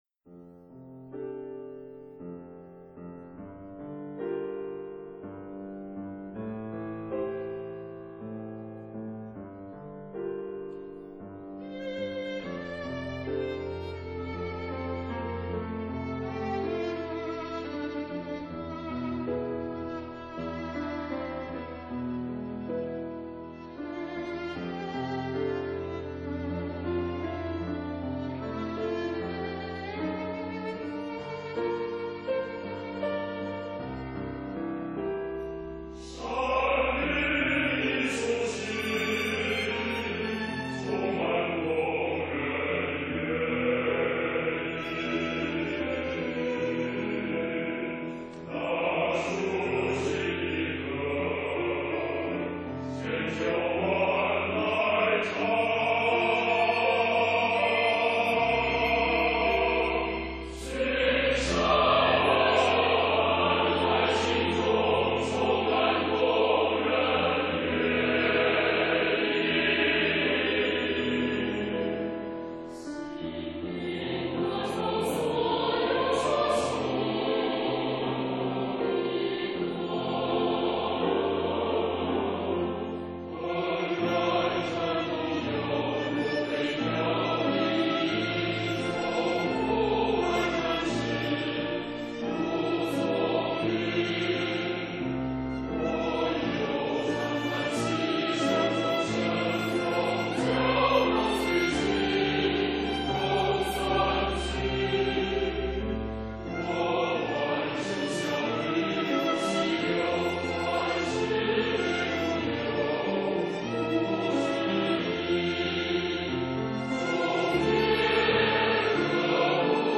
大合唱